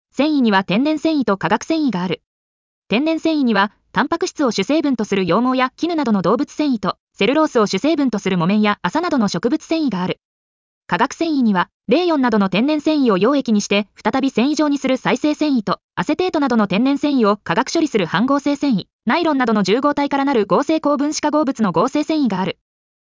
• 耳たこ音読では音声ファイルを再生して要点を音読します。